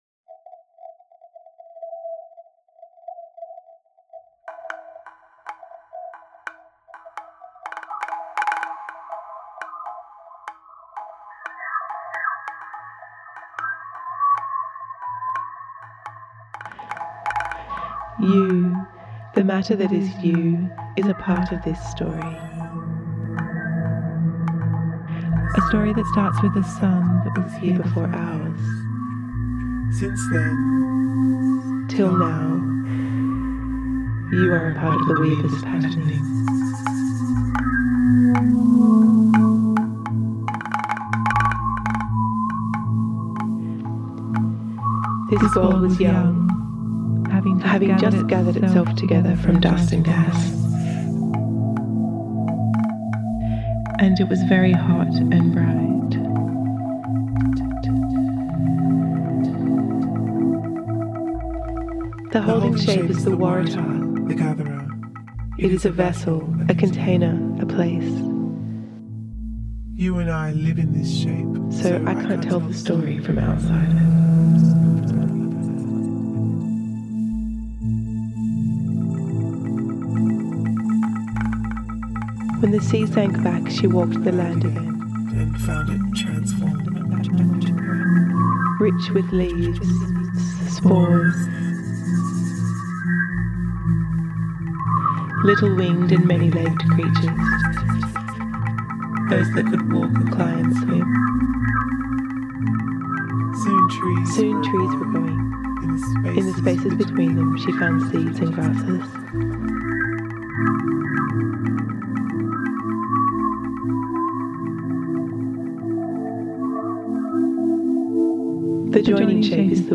This audio piece was commissioned for NGV’s Sampling the Future exhibition to be played as part of an installation of Transformation of Weaver mirrors.
Sound composition